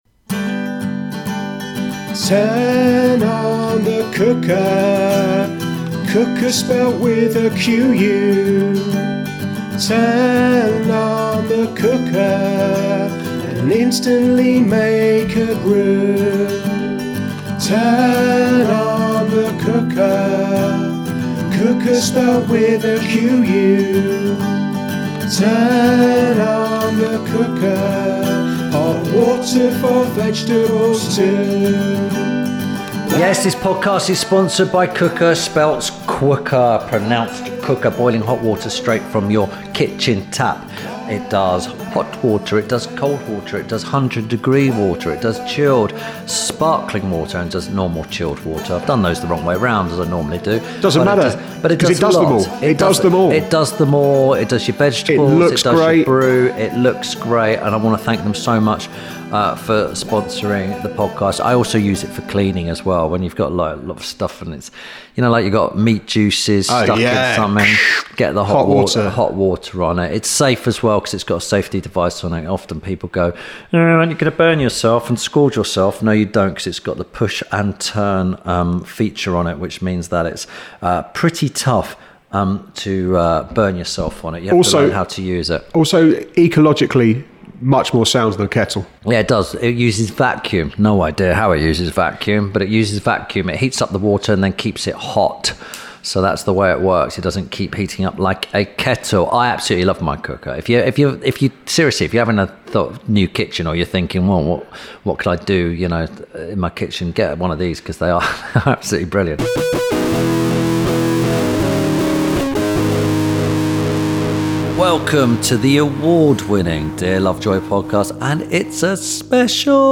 Ep. 157 – TOM KERRIDGE – (REVISIT) – INTERVIEW SPECIAL.
This week we revisit Tim Lovejoy’s chat with Michelin starred chef, restaurateur, and TV presenter Tom Kerridge (29/04/18). Tim and Tom discuss Tom’s early years in the restaurant industry, how he runs his businesses with an emphasis on people and how he lost a lot of weight.